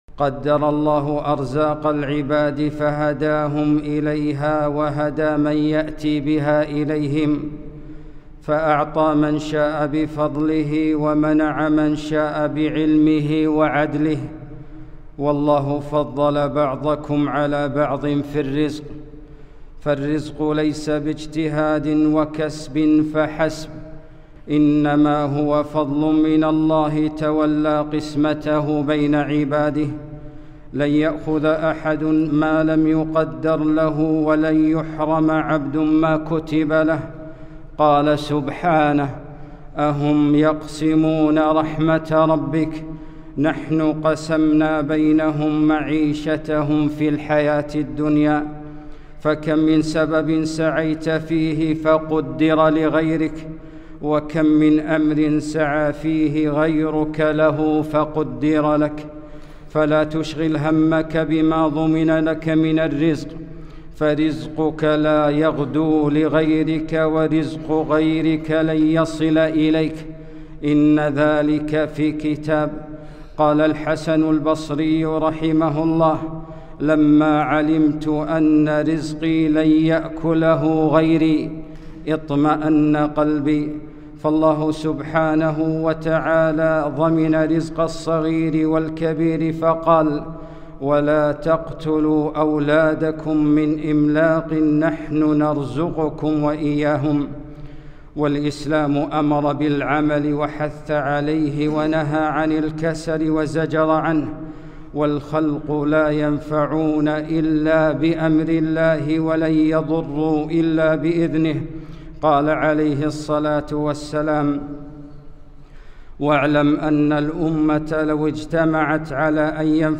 خطبة - الشيطان يعدكم الفقر